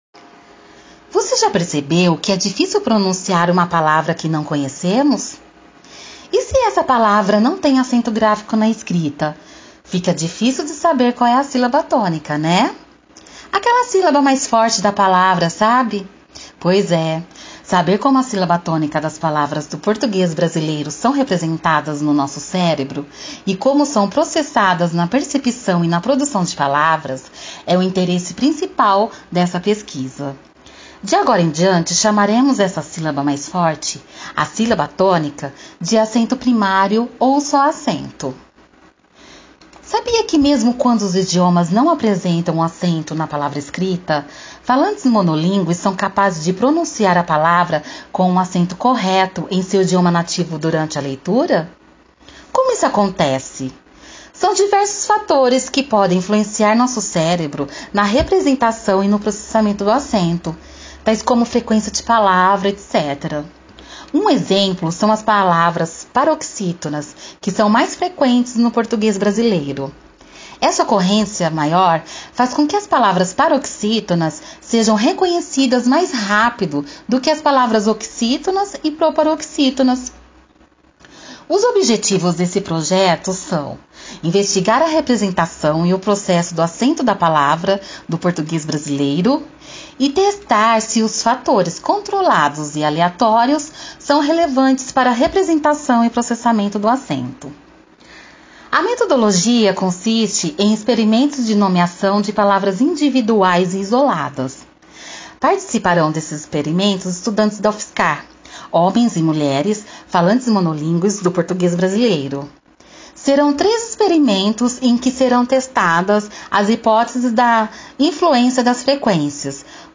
Transcrição do áudio de descrição do painel